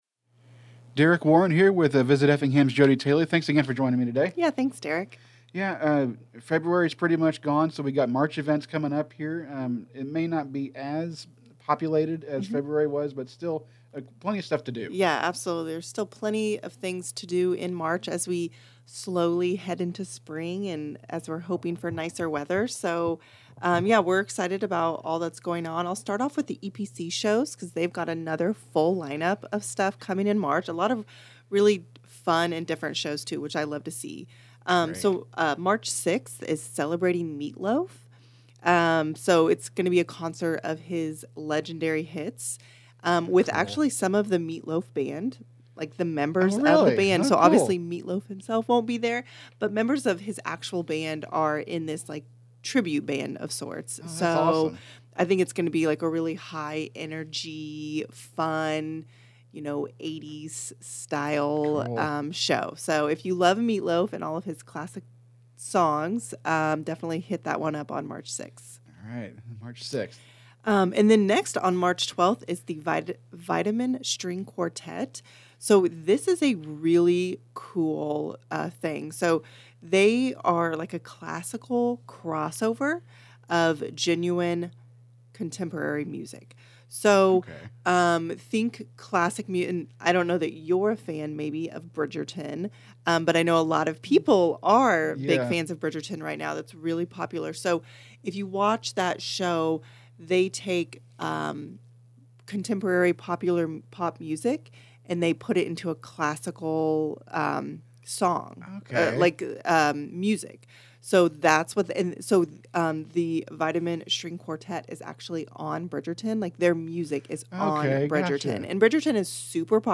This is part of a monthly series of interviews that will continue next month.